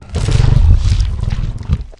flesh_eat_3.ogg